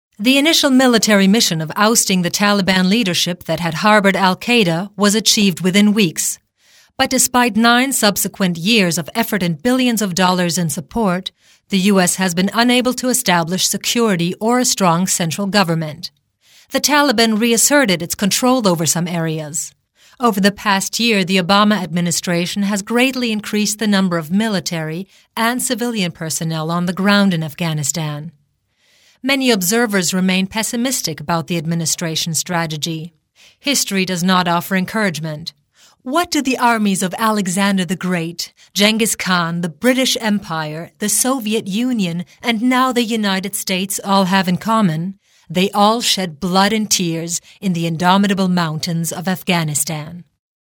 middle west
Sprechprobe: Industrie (Muttersprache):
Native speaker in English (U.S.) and German